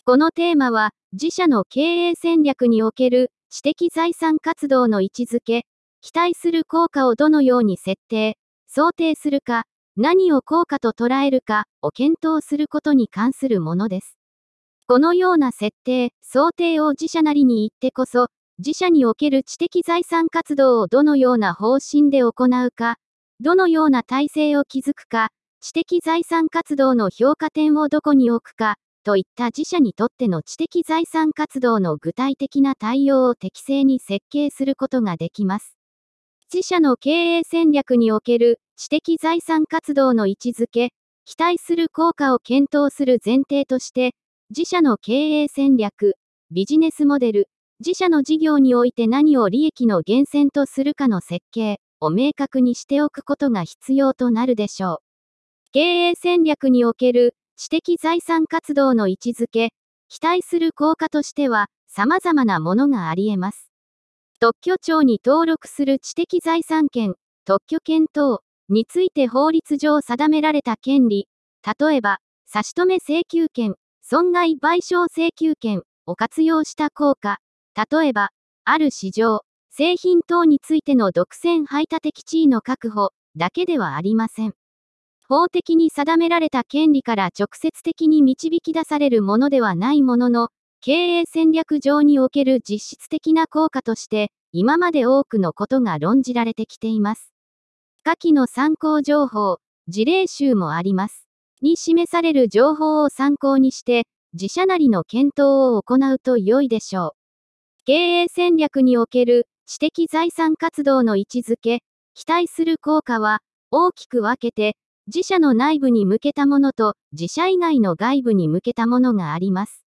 テーマの説明音声データ＞＞